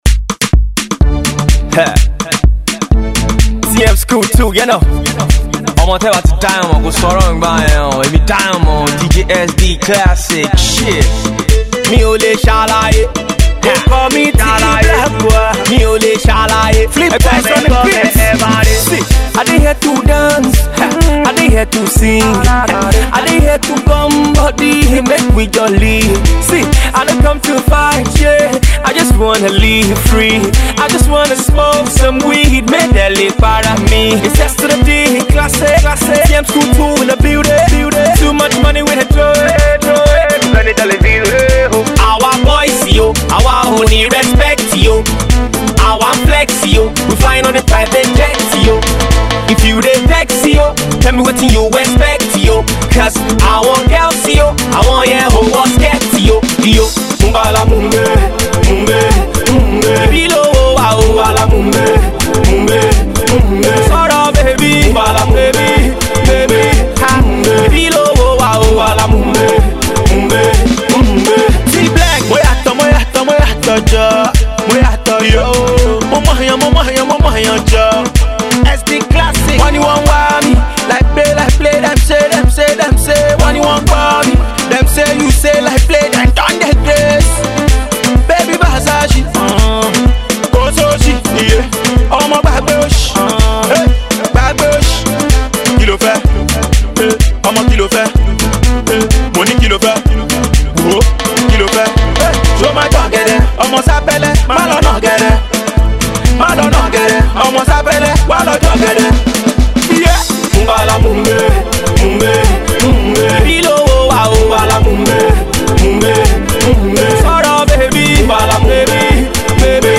Street Pop